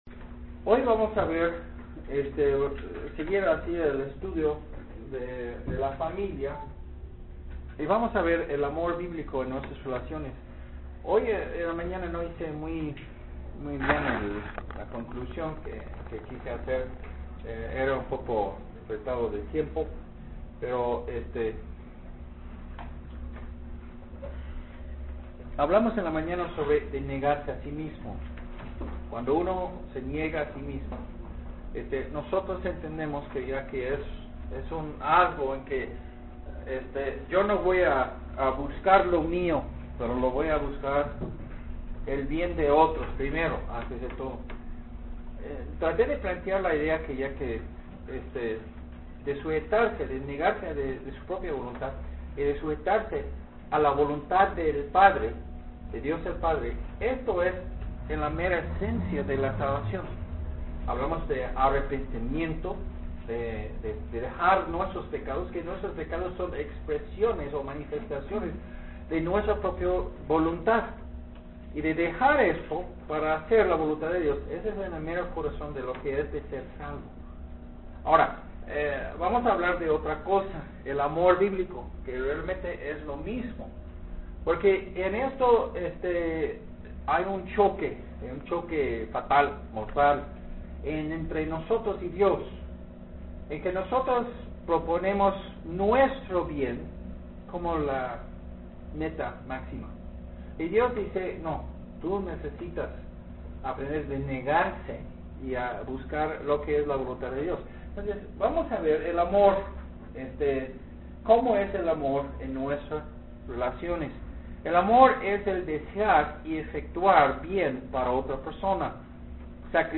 Amor Bíblico en nuestras Relaciones es un sermón sobre el hogar y cómo nuestras relaciones deben basarse en el amor bíblico